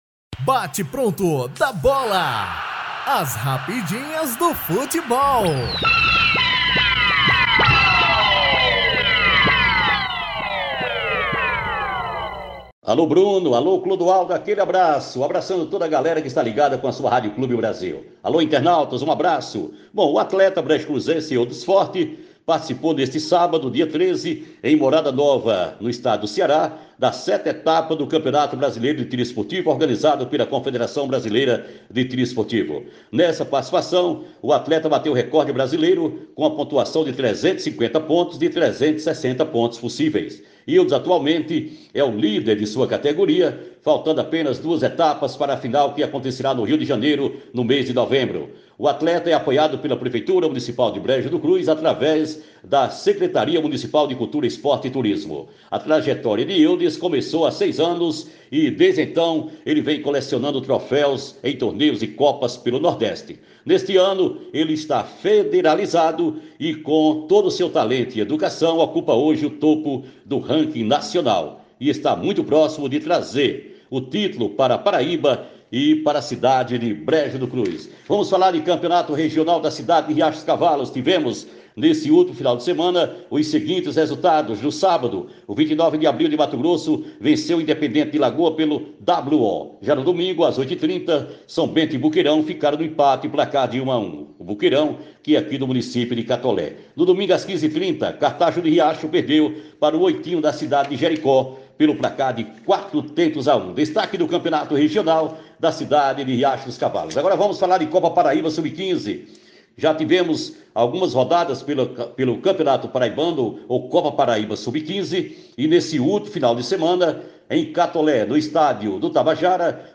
Esportes